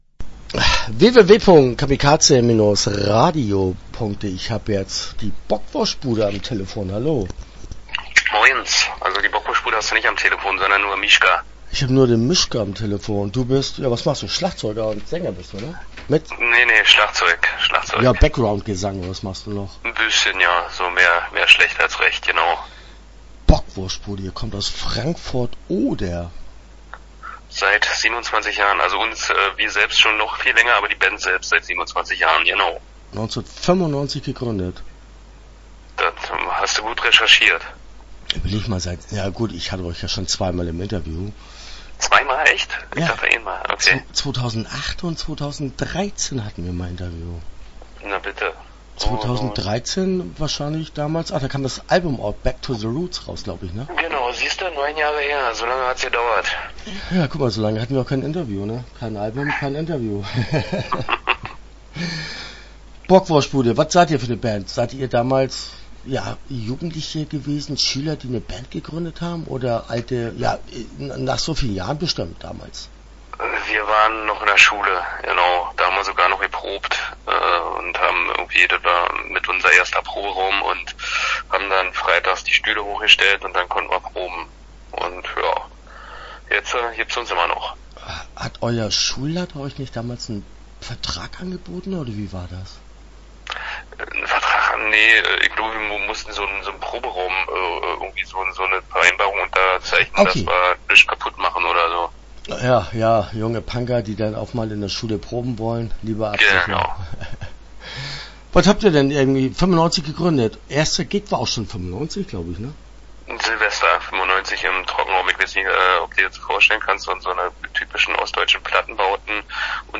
Bockwurschtbude - Interview Teil 1 (11:30)